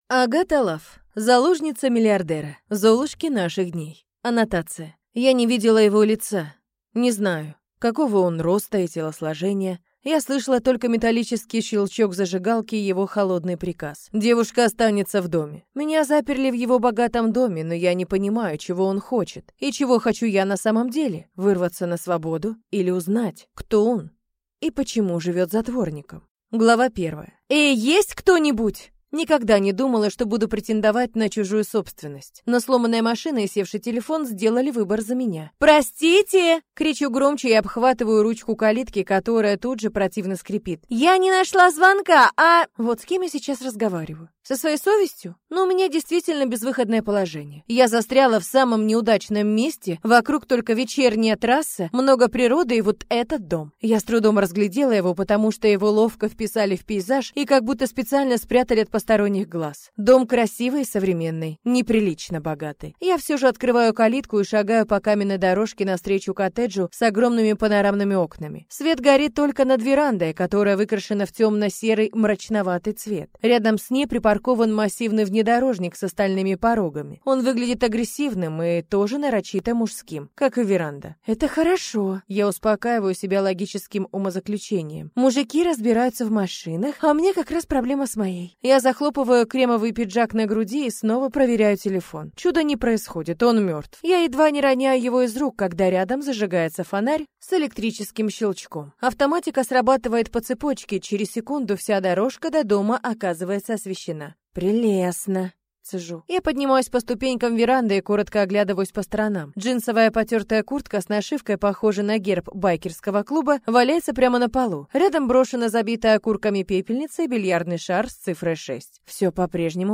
Аудиокнига Заложница миллиардера | Библиотека аудиокниг